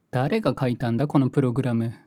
ハッカー ボイス 声素材 – Hacker Cracker Voice
Voiceボイス声素材